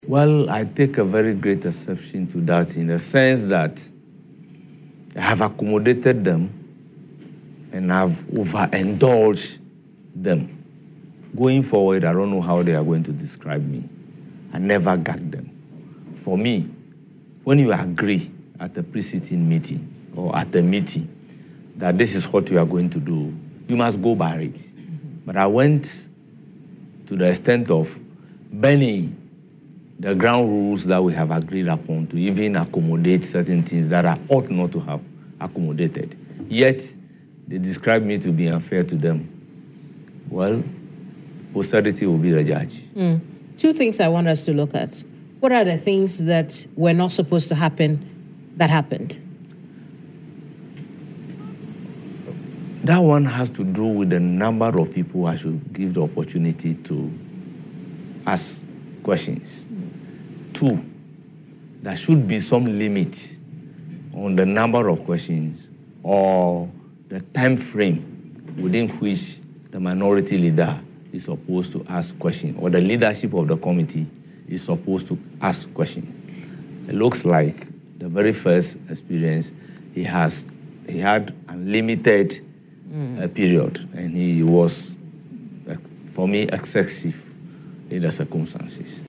“I went to the extent of bending the ground rules that we had agreed upon to even accommodate certain things that I ought not to have accommodated, yet they describe me as being unfair to them. Well, posterity will be the judge,” he stated in an interview on Accra-based TV3.